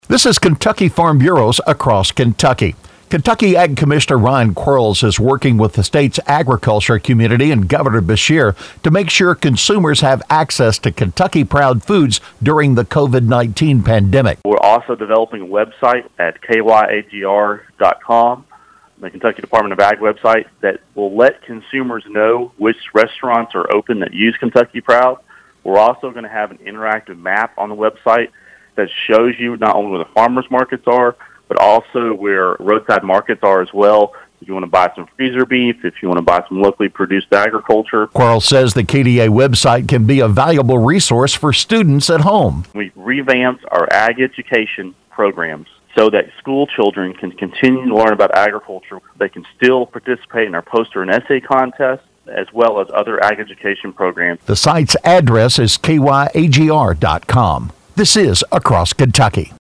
Kentucky Ag Commissioner Ryan Quarles talks about how consumers can find Kentucky Proud products during the coronavirus pandemic.